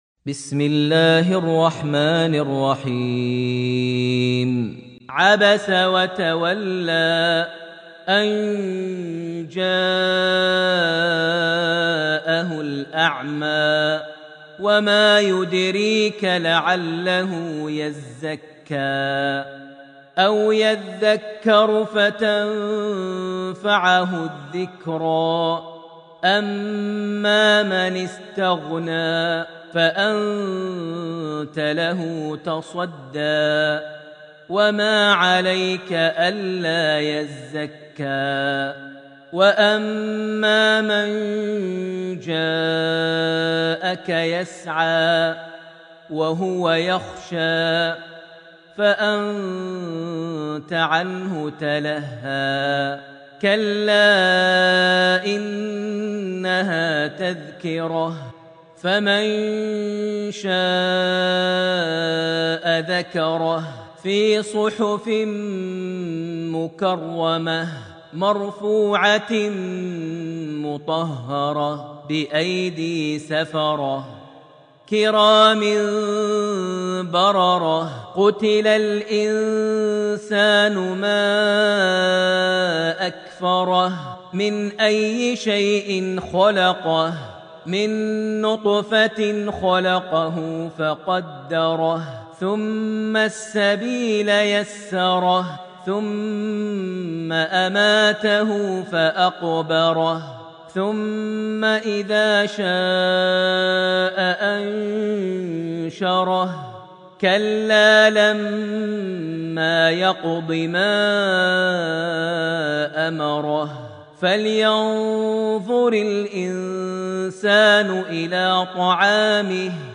Surah Abs > Almushaf > Mushaf - Maher Almuaiqly Recitations